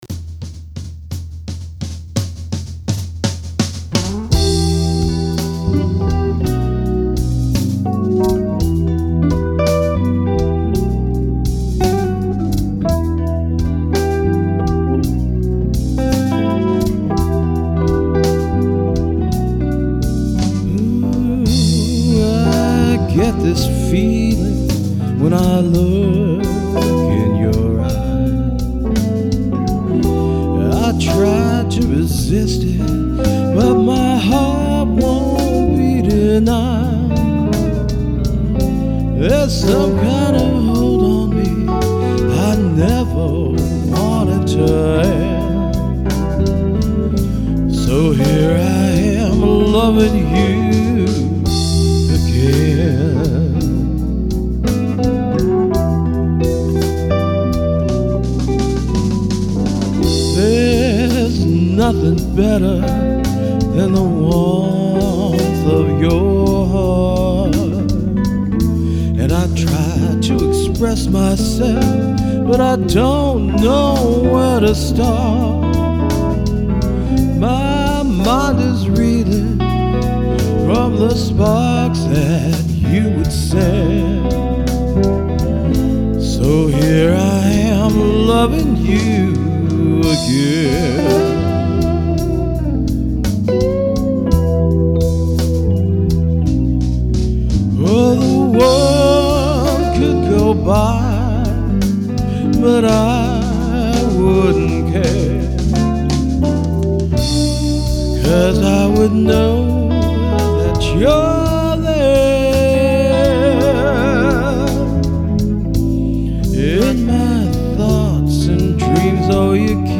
Unfortunately, the stock drum tracks in GarageBand just don’t cover the blues very well, so I finally found some decent audio drum loops that I could use. Ostensibly, this is a song about everlasting love, and how in marriage or even lifelong relationships, despite their occasional downs, if you truly love someone, you’ll return to them.
With this song, I wanted to capture a smoky lounge with a jazz quartet kind of groove. And BTW, the guitar in this was recorded using IK Multimedia Amplitube Fender.